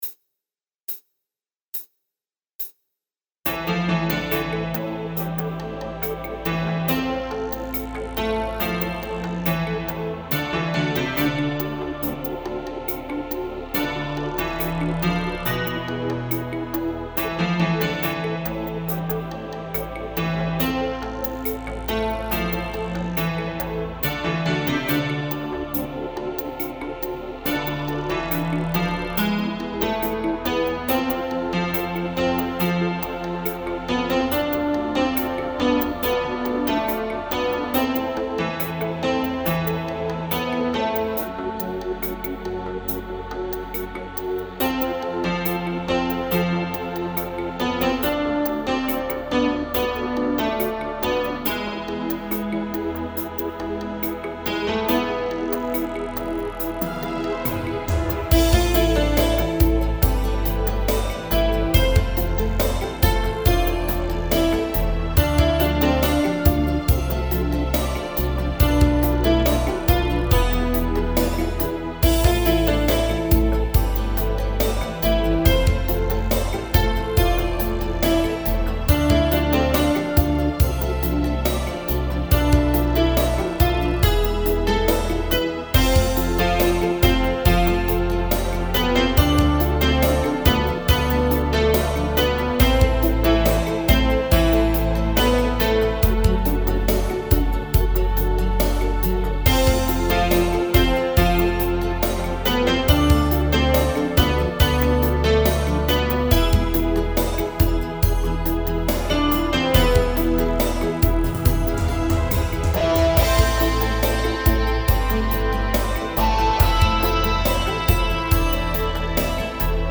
Инструментальная композиция